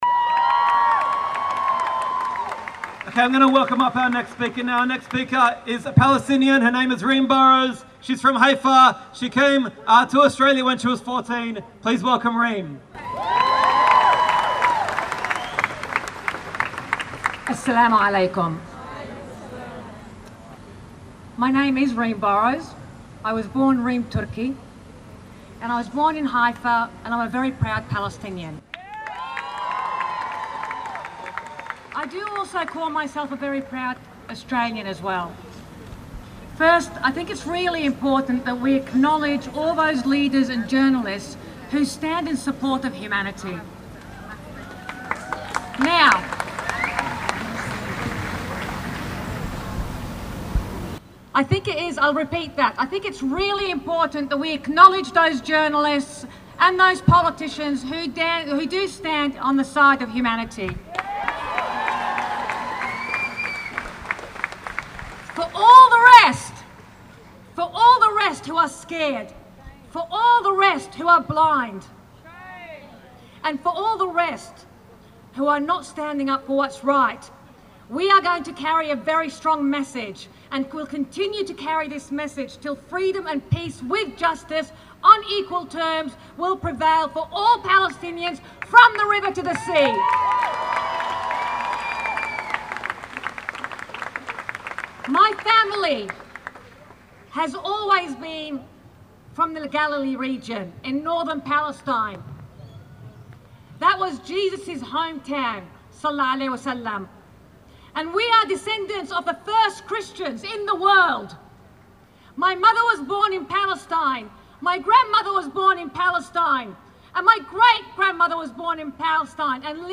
unifying message. at the Dec 3 Sydney rally for peace.
speaker 1 sydney rally 3 dec_1.mp3